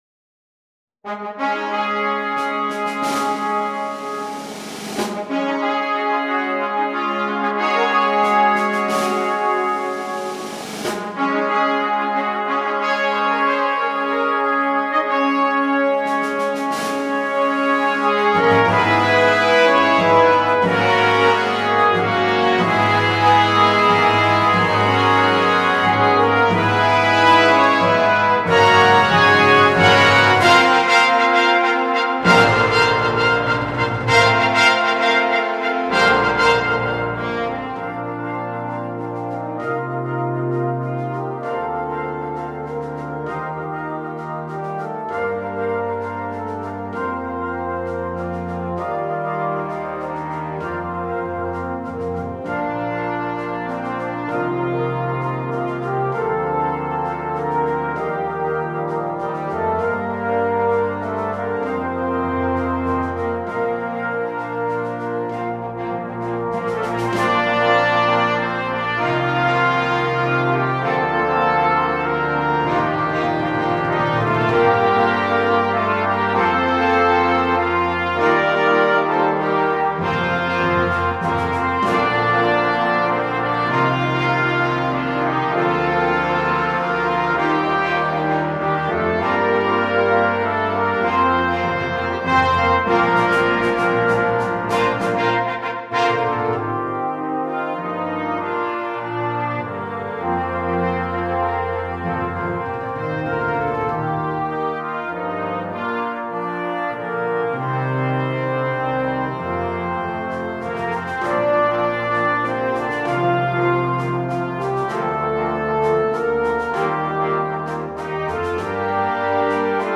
2. Banda de metales
Banda completa
Música ligera